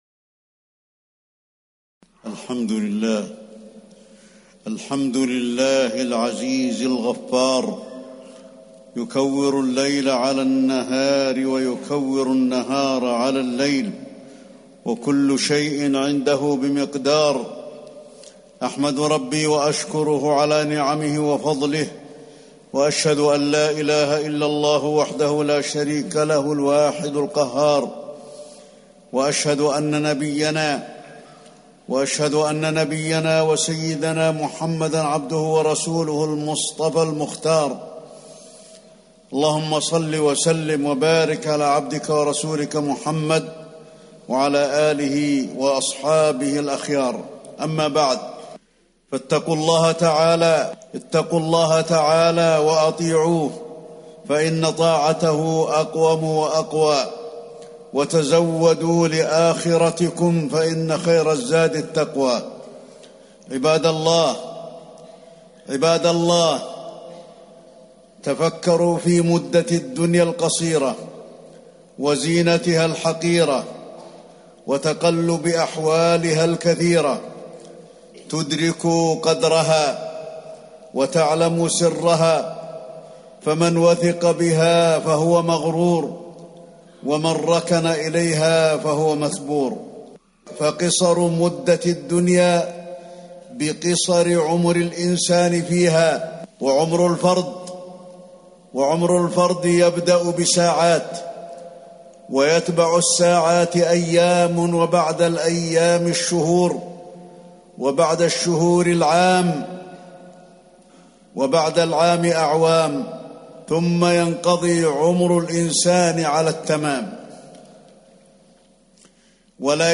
تاريخ النشر ٢٨ ربيع الثاني ١٤٤٠ هـ المكان: المسجد النبوي الشيخ: فضيلة الشيخ د. علي بن عبدالرحمن الحذيفي فضيلة الشيخ د. علي بن عبدالرحمن الحذيفي الدنيا متاع الغرور